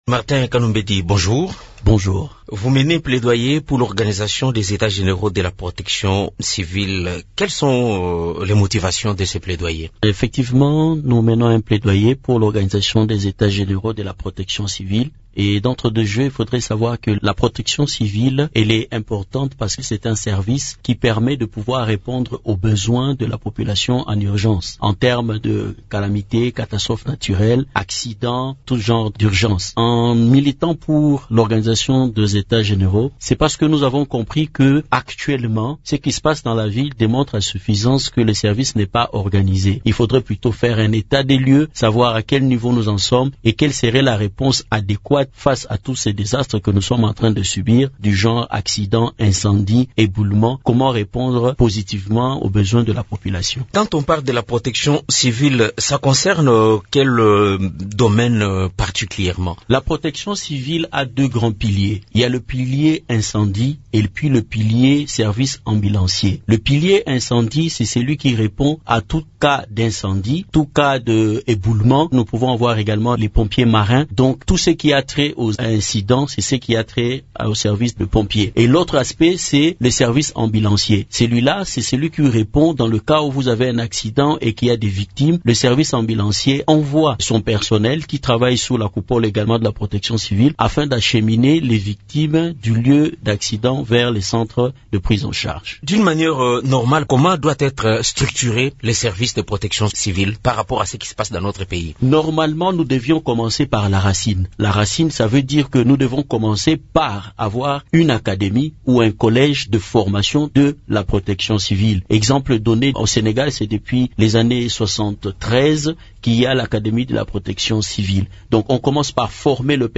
Dans une interview à Radio Okapi, cet expert a expliqué que ces états généraux pourraient permettre de mieux organiser le service de la protection civile et ainsi outiller la RDC à gérer les catastrophes et les sinistres.